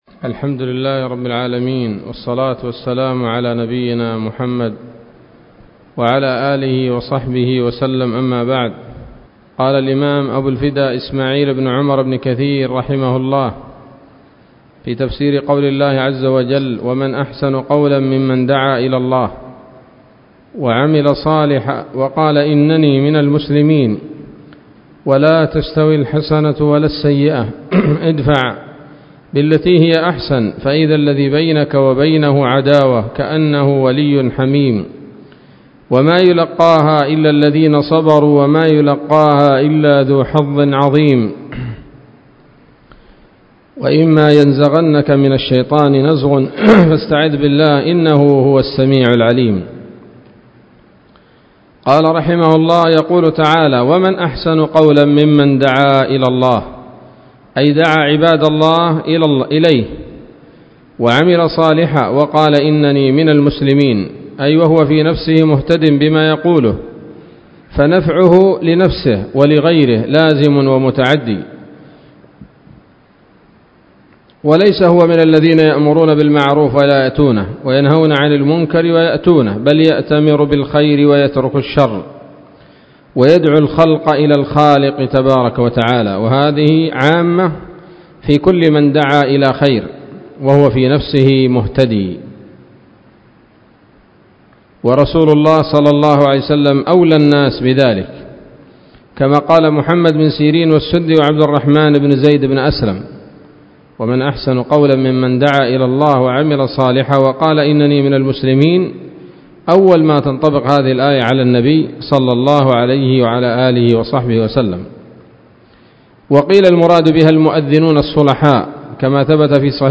الدرس التاسع من سورة فصلت من تفسير ابن كثير رحمه الله تعالى